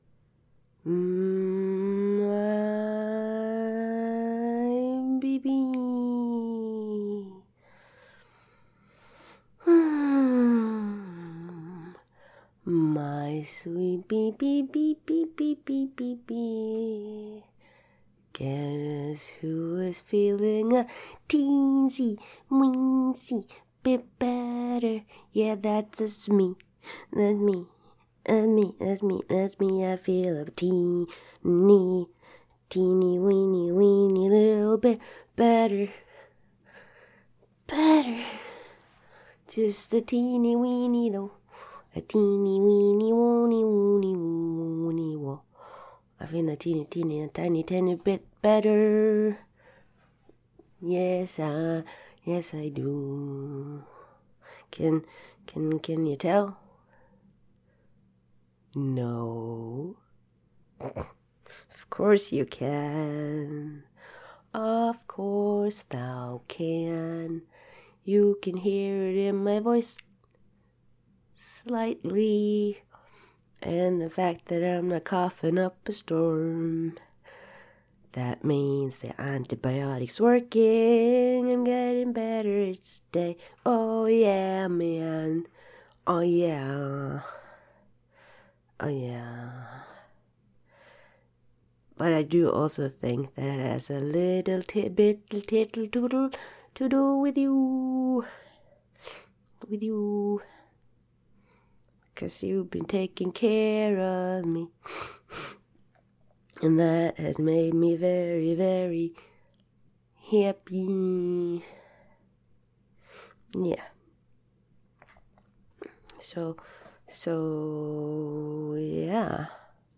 Girlfriend Roleplay scratchy voice